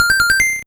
ChallengeMedalAward.wav